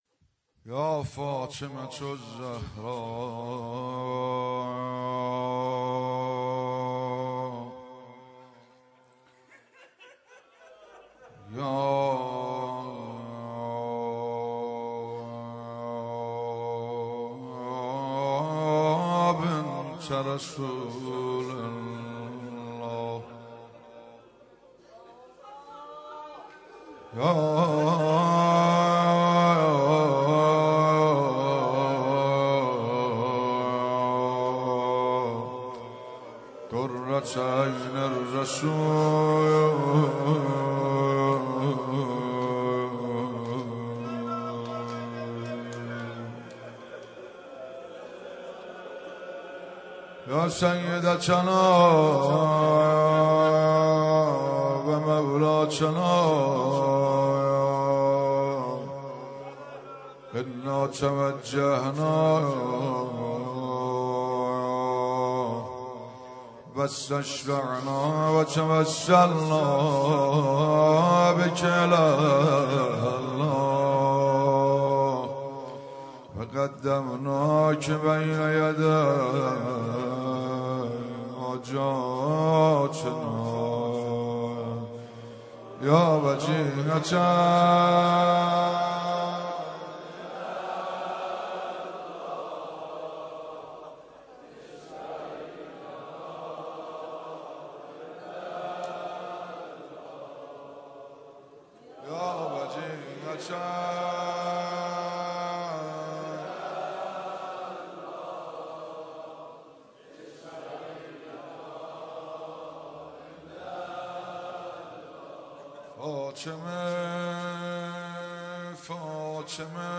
شب سوم فاطمیه 95 - روضه - حضرت زهرا سلام الله علیها
مداحی